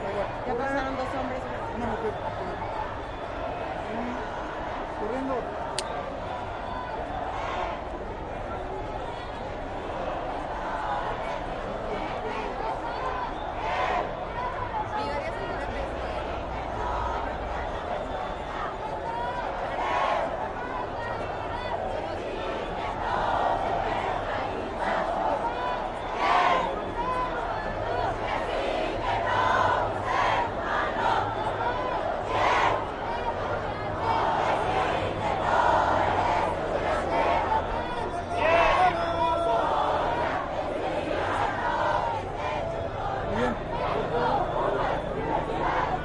描述：1968年，为了纪念被杀害的学生，墨西哥的一群暴徒......街道，人群，学生，人，墨西哥，西班牙语的一切
Tag: 人群 抗议 暴民